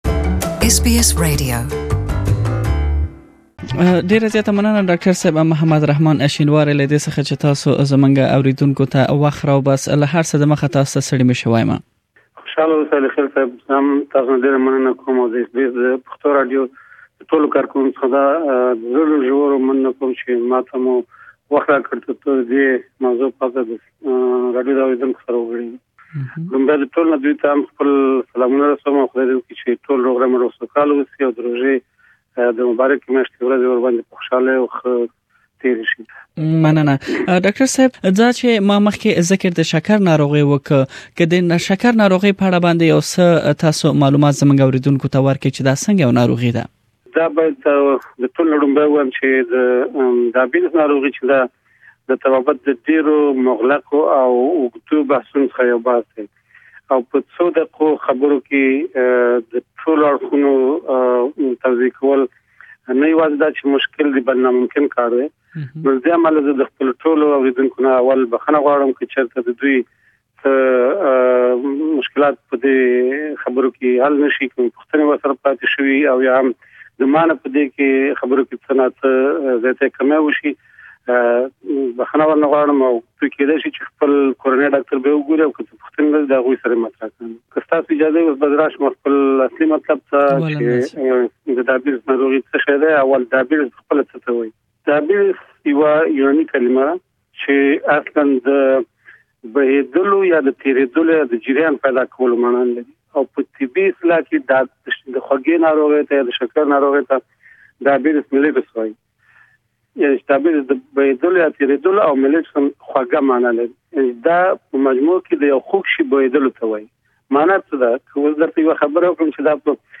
please listen to the first part of the interview here.